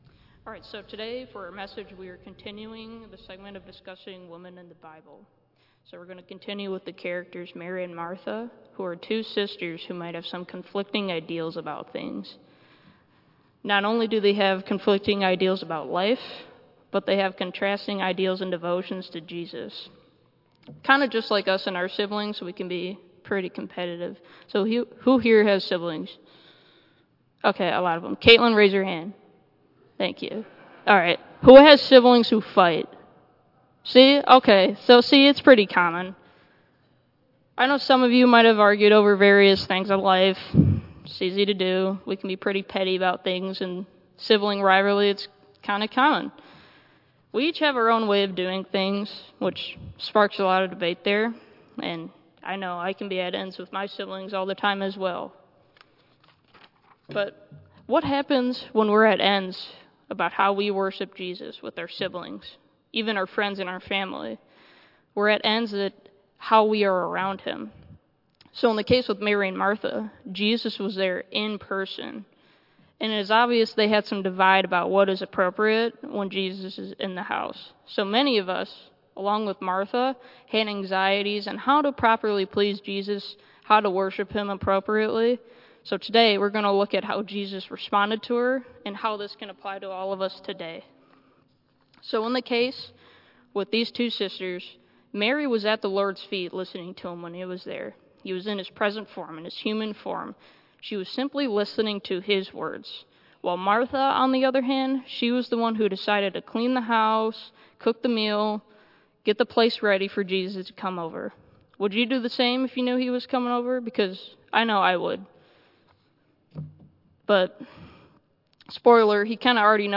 Women of the Bible II Message Series Based on Luke 10:38-42. Tagged with Michigan , Sermon , Waterford Central United Methodist Church , Worship Audio (MP3) 3 MB Previous Esther - Much Like Me Next Salome - Mother of the Sons of Thunder